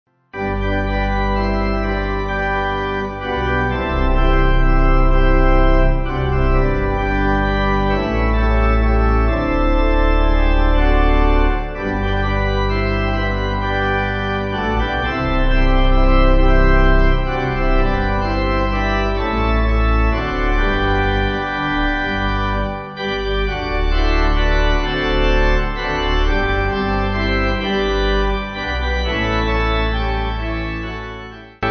(CM)   3/G